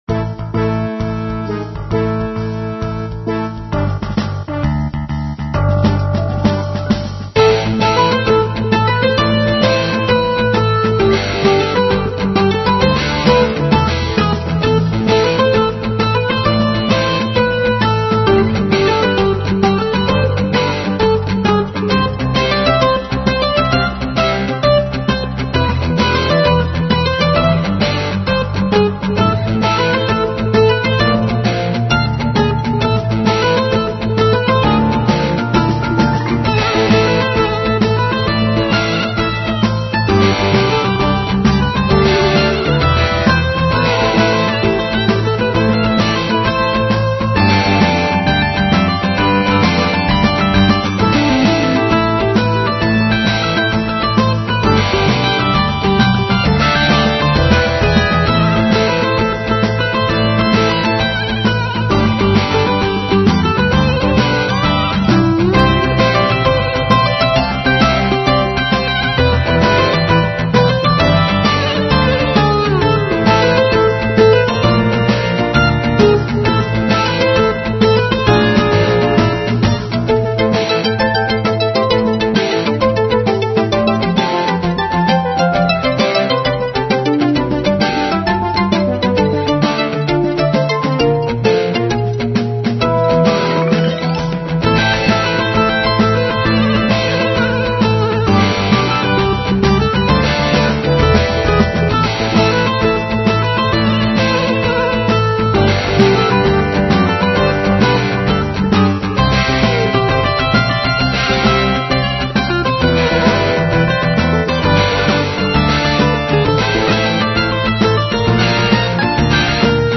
Instrumental Rock with feel of combat flight